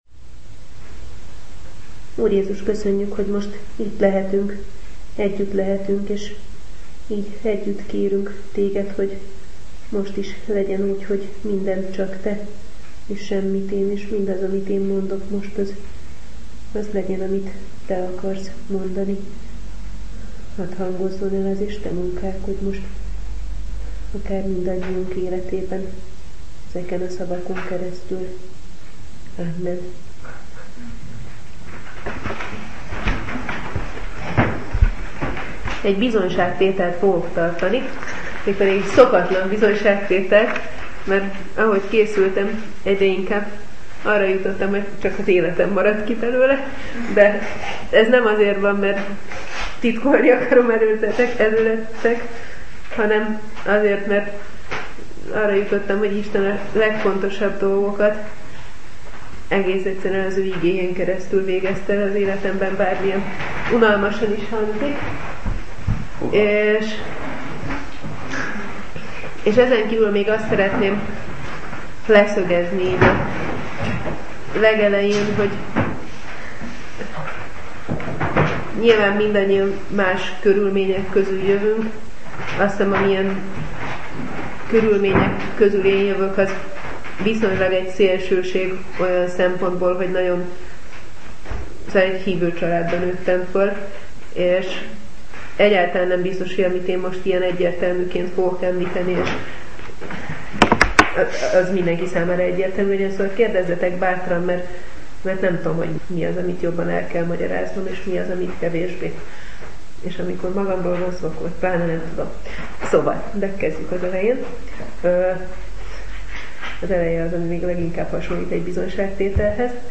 Bizonyságtétel
(Elhangzott a 2009-es tavaszi csendeshétvégén.)